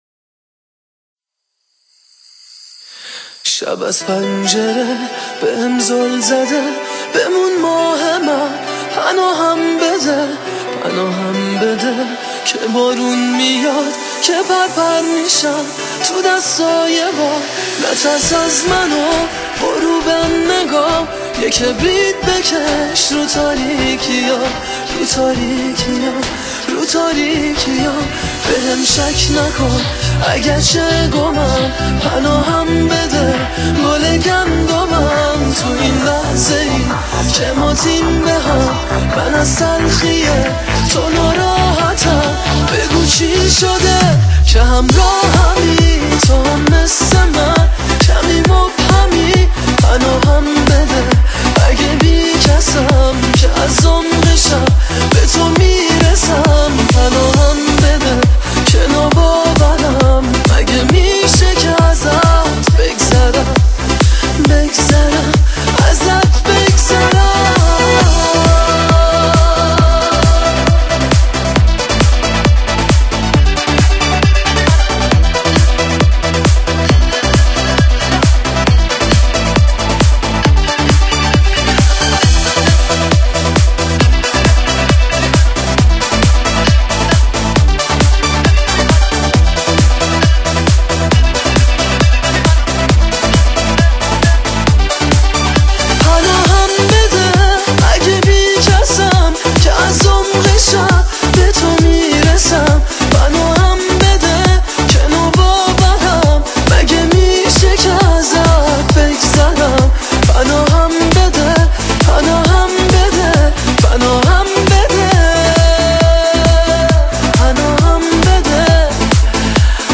آهنگ احساسی